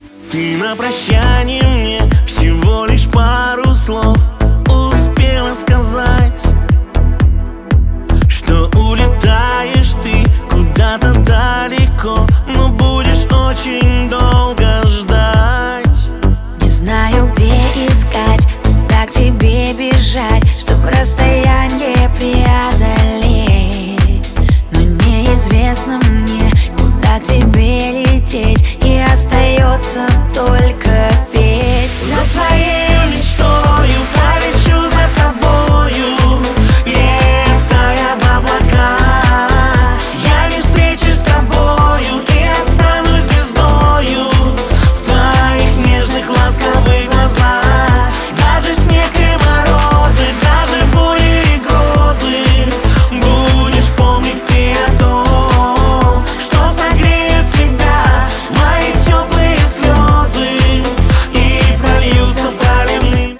• Качество: 128, Stereo
поп
дуэт